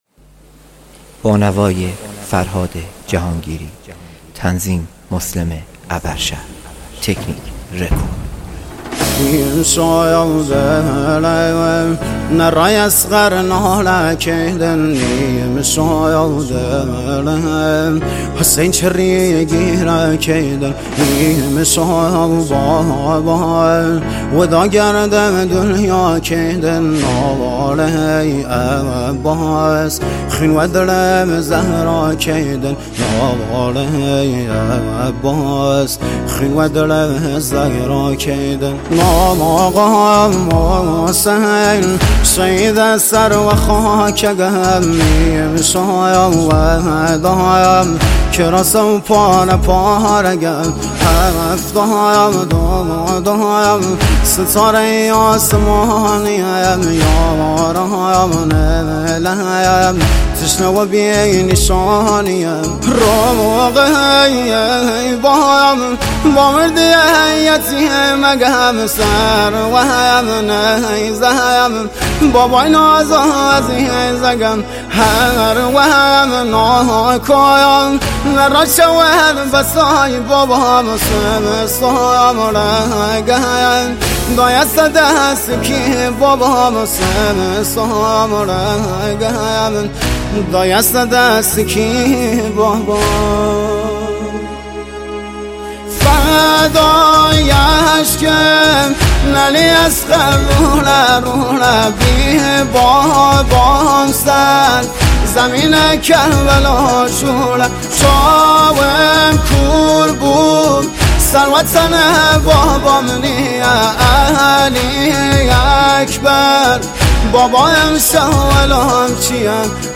آهنگ مداحی
مداحی محرم